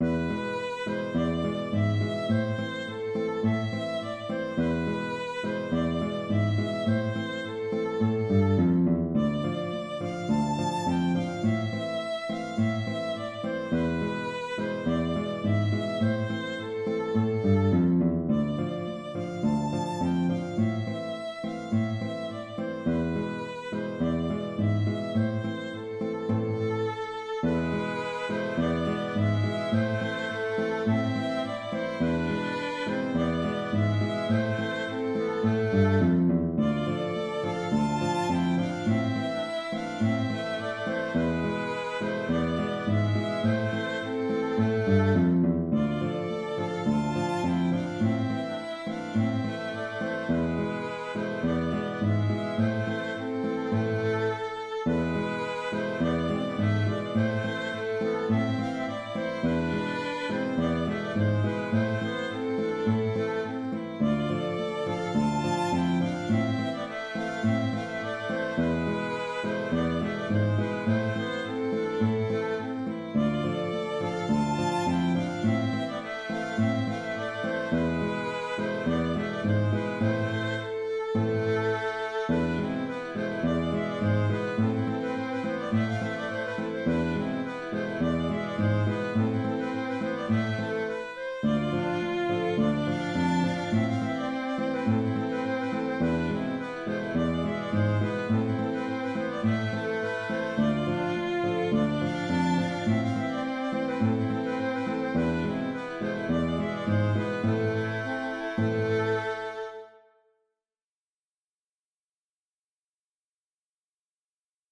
Trad. Russie
J’ai repris sa partition pour pouvoir jouer les voix avec des instruments distincts, par exemple accordéon et violon, ou deux violons… Le contrechant peut prendre alors un peu de liberté, tout en essayant de garder l’esprit d’origine.